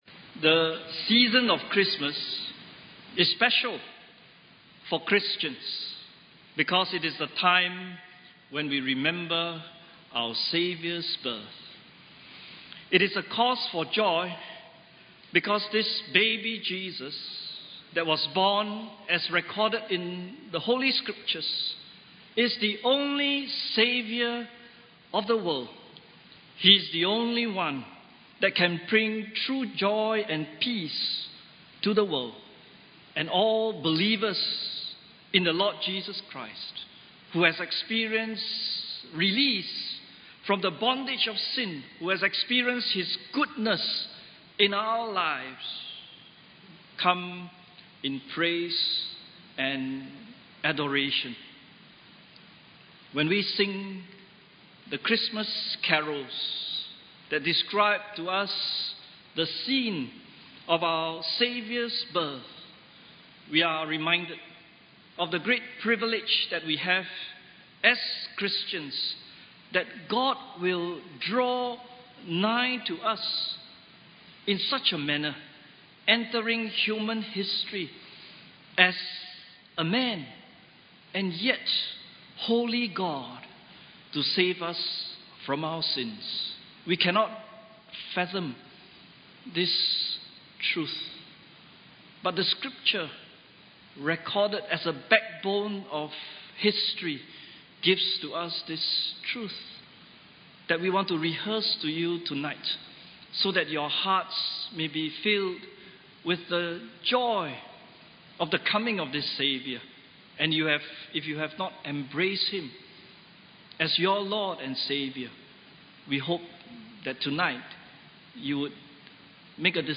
Christmas Praise Service 2016 – The Joy of Christmas